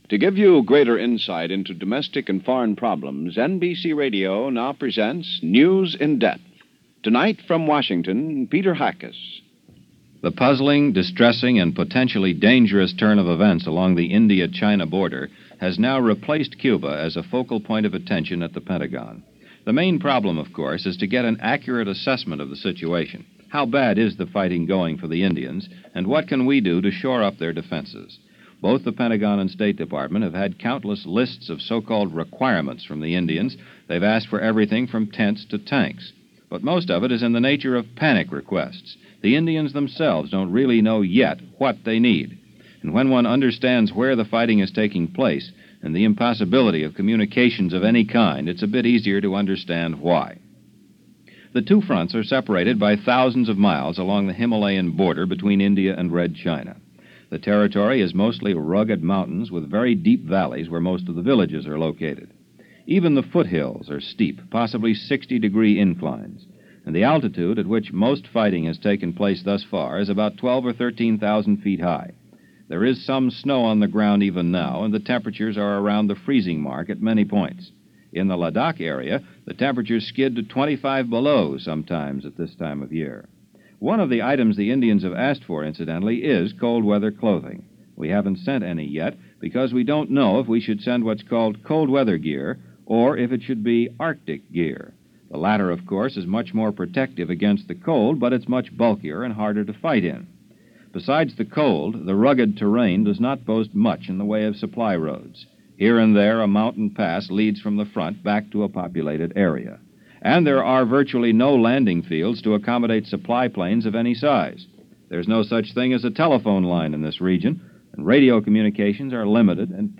Here is that background report on fighting between India and China from Novebember 20th 1962.